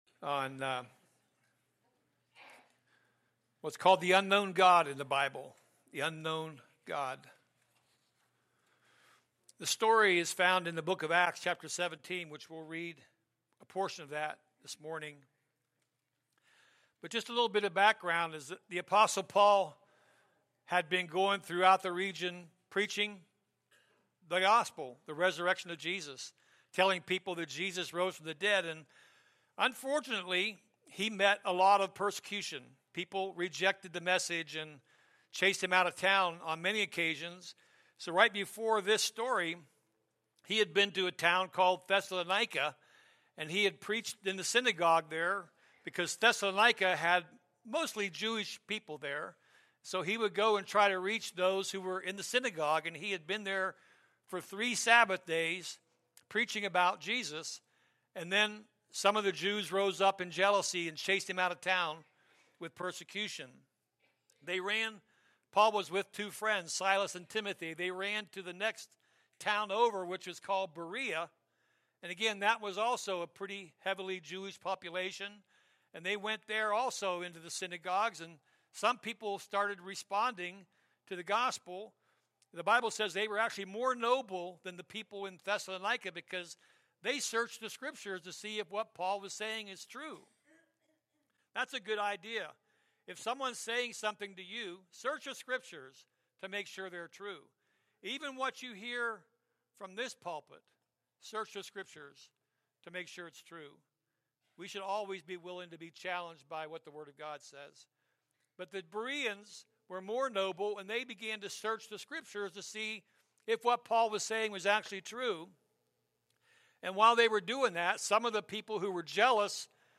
From Series: "Sunday Morning Service"